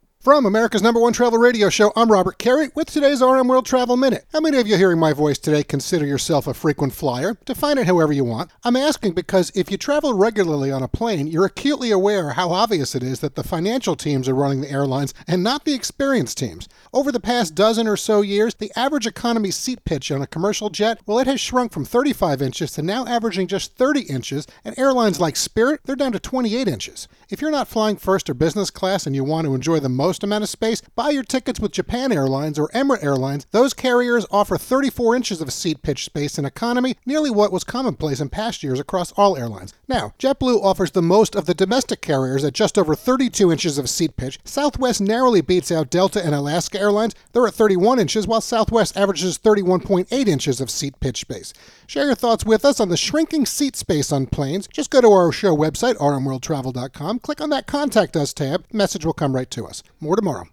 America's #1 Travel Radio Show